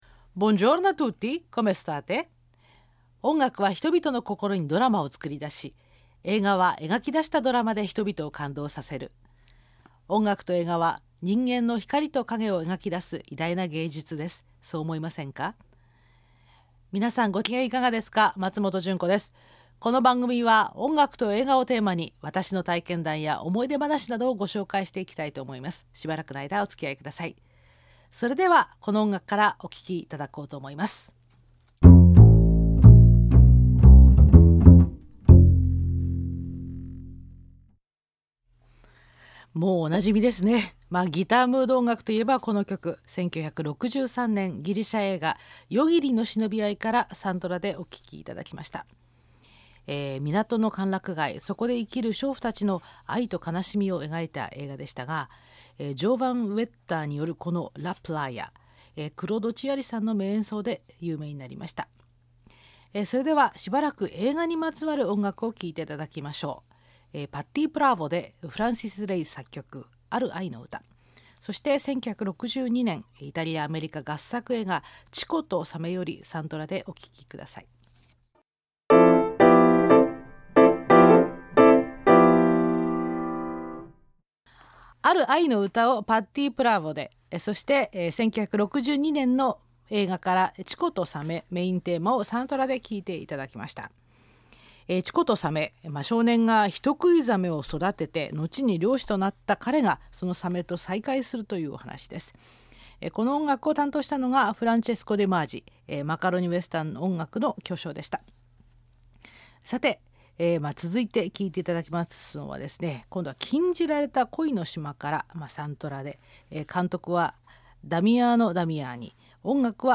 ※著作権保護のため楽曲部分は削除されておりますので、あらかじめご了承の上ご利用ください。